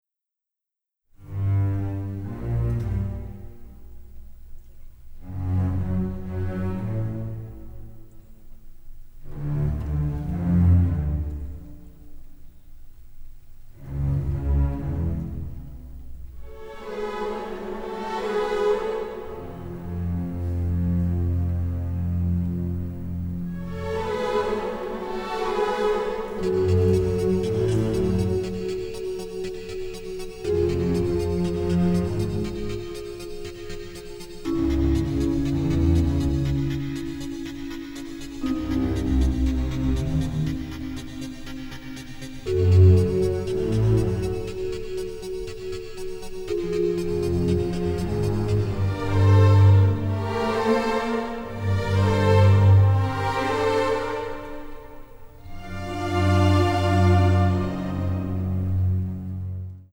romantic noir score